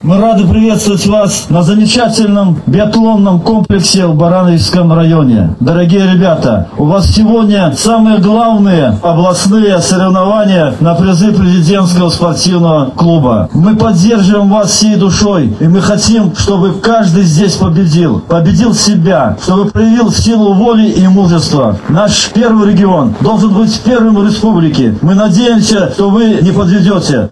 С приветственным словом обратился заместитель председателя райисполкома Михаил Борисевич.